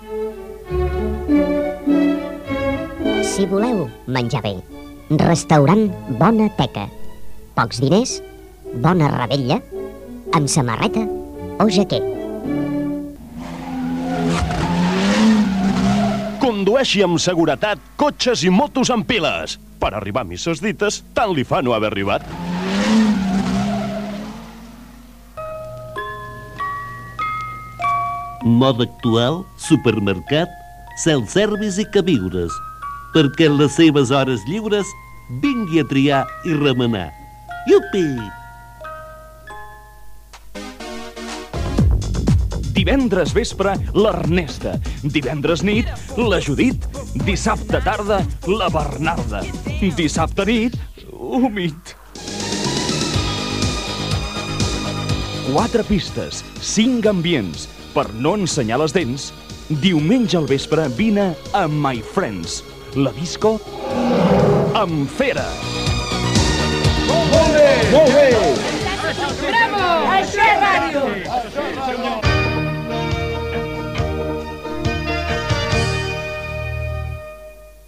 Bateria de falques publicitàries fictícies com a felicitació de Nadal de l'any 1990